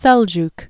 (sĕljk, sĕl-jk)